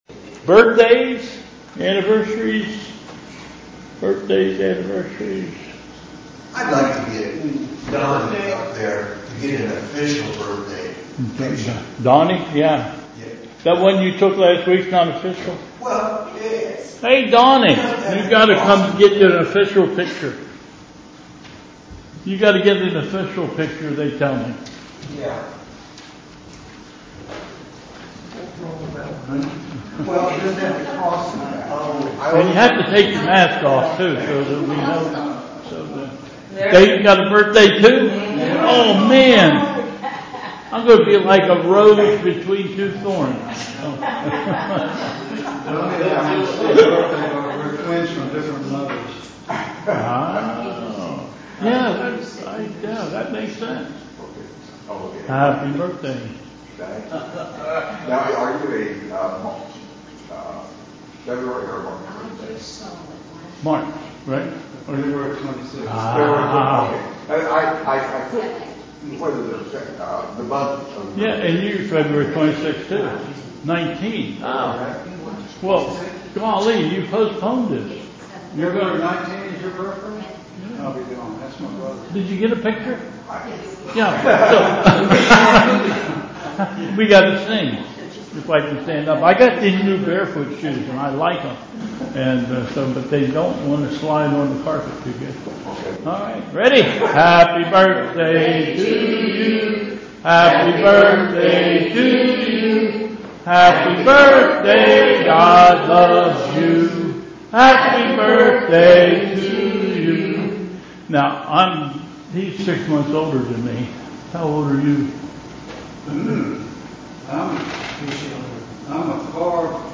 Bethel Church Service
Welcome and Announcements